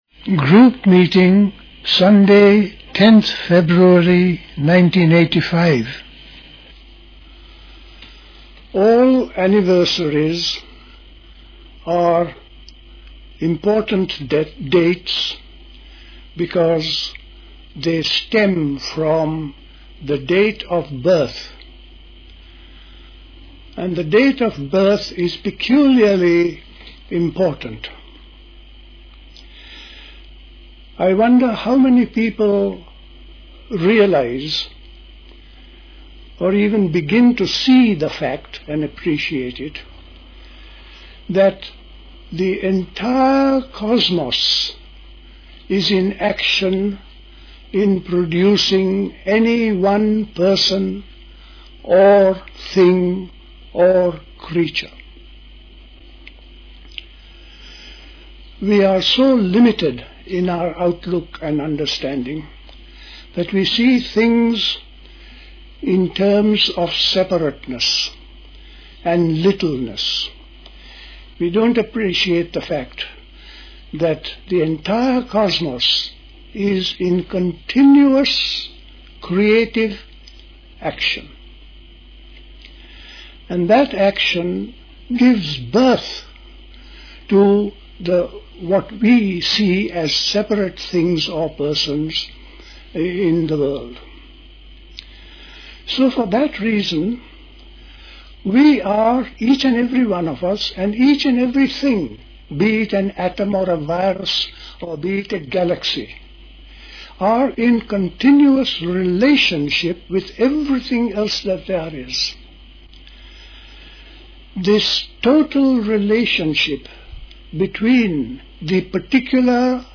A talk